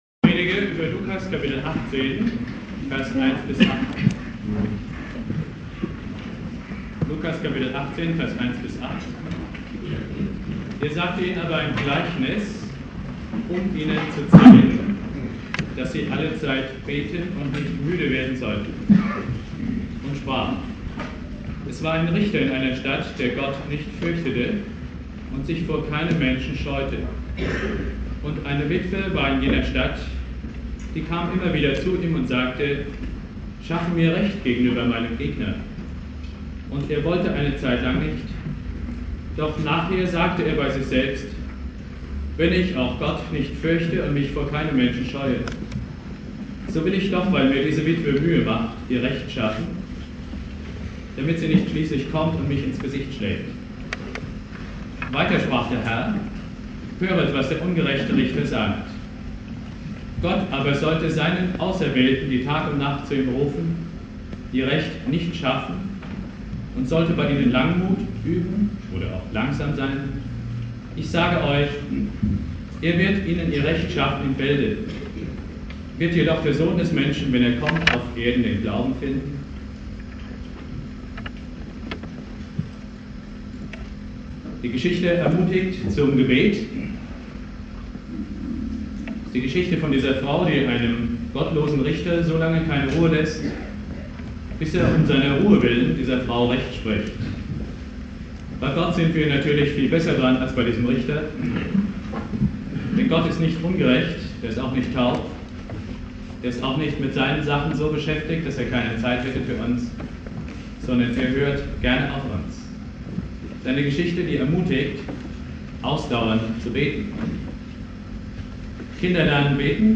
Predigt
Thema: "Ermutigung zum Gebet" (mit Außenmikro aufgenommen) Bibeltext: Lukas 18,1-8 Dauer